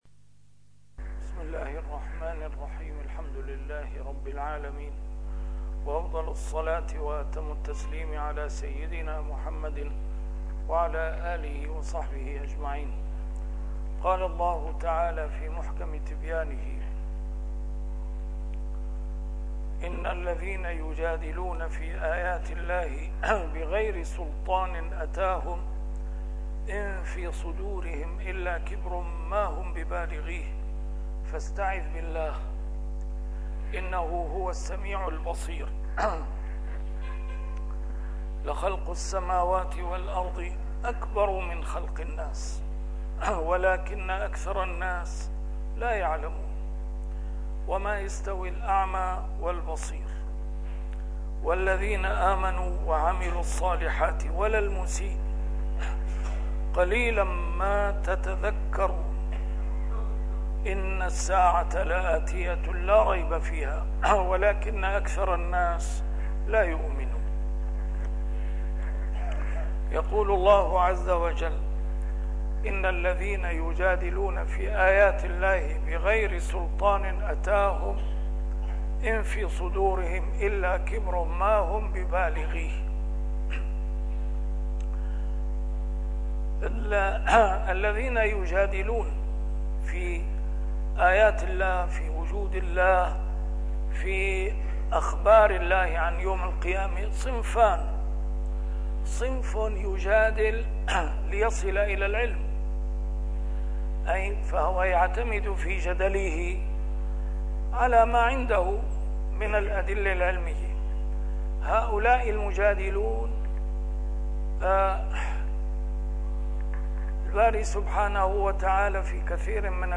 A MARTYR SCHOLAR: IMAM MUHAMMAD SAEED RAMADAN AL-BOUTI - الدروس العلمية - تفسير القرآن الكريم - تسجيل قديم - الدرس 523: غافر 56-59 إعادة